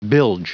Prononciation du mot bilge en anglais (fichier audio)
Prononciation du mot : bilge